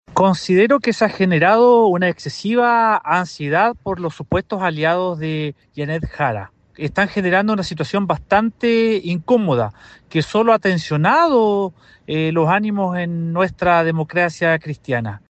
El jefe de bancada de los diputados de la tienda, Héctor Barría, sostuvo que las declaraciones que han surgido desde el oficialismo “solo han tensionado los ánimos en su partido”.